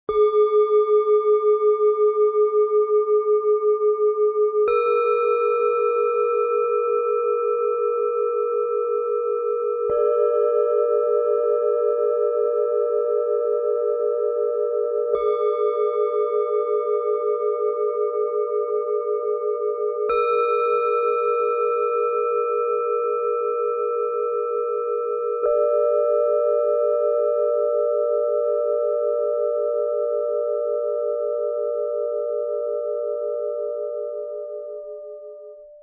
Set aus 3 Planetenschalen für Meditation, Klangpädagogik & mobile Klangarbeit
Die Schalen werden geschmiedet und nicht gegossen - für eine besonders tiefe und feine Schwingung.
Tiefster Ton: Neptun
Bengalen Schale, Matt, 13,9 cm Durchmesser, 7 cm Höhe
Mittlerer Ton: Mond
Höchster Ton: Saturn